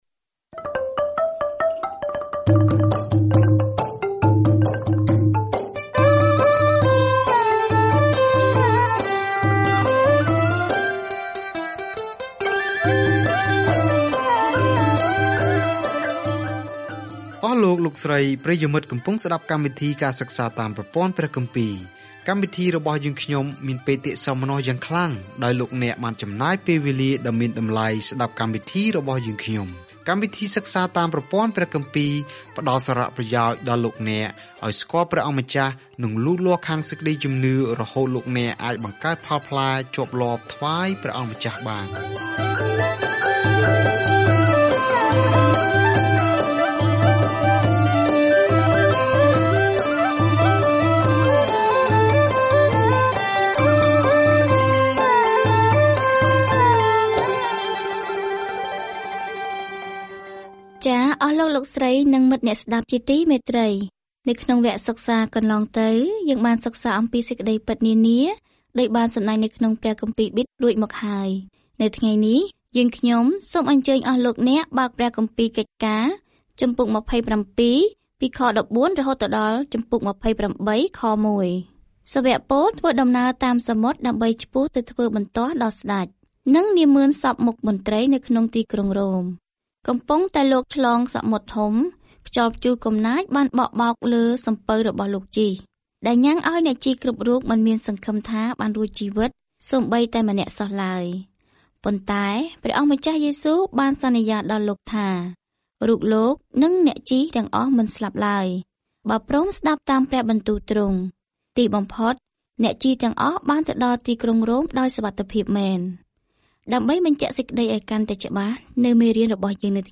កិច្ចការរបស់ព្រះយេស៊ូវចាប់ផ្ដើមនៅក្នុងសៀវភៅដំណឹងល្អឥឡូវនេះបន្តតាមរយៈព្រះវិញ្ញាណរបស់ទ្រង់ ខណៈដែលព្រះវិហារត្រូវបានគេដាំនិងរីកលូតលាស់ពាសពេញពិភពលោក។ ការធ្វើដំណើរជារៀងរាល់ថ្ងៃតាមរយៈកិច្ចការ នៅពេលអ្នកស្តាប់ការសិក្សាជាសំឡេង ហើយអានខគម្ពីរដែលជ្រើសរើសពីព្រះបន្ទូលរបស់ព្រះ។